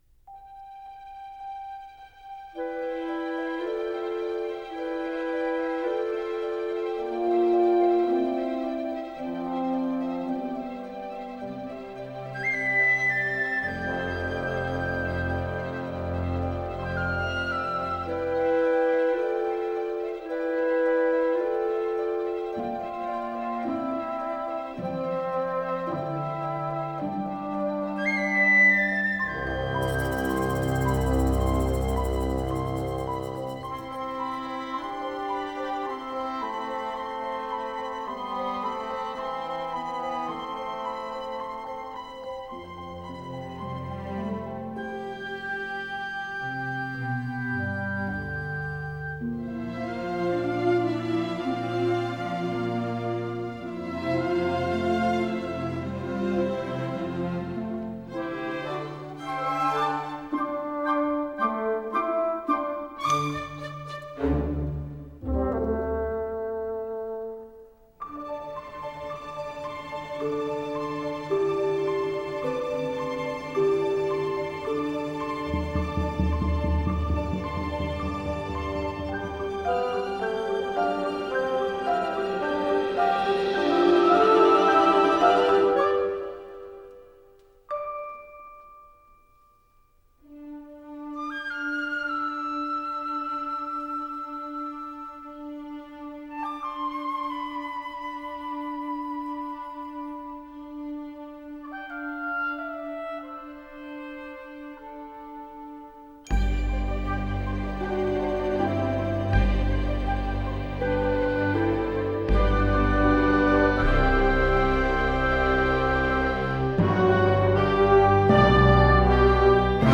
На этой странице вы найдете саундтрек к мультфильму \